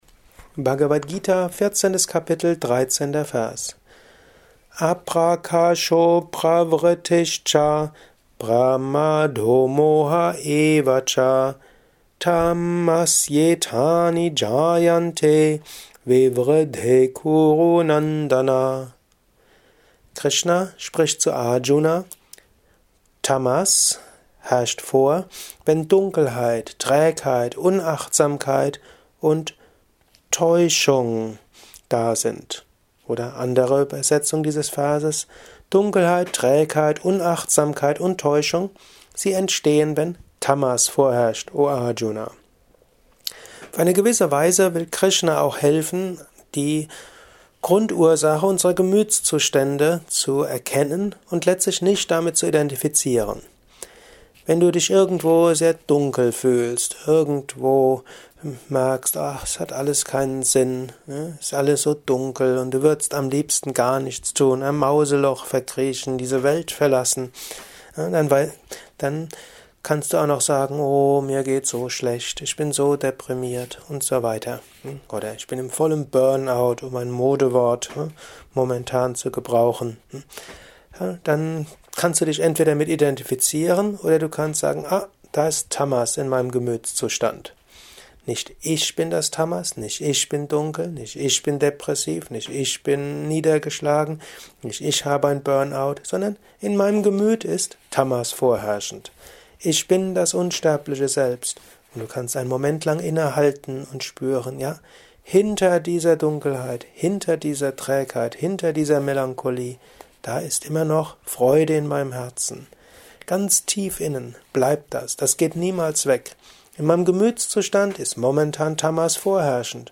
Dies ist ein kurzer Kommentar als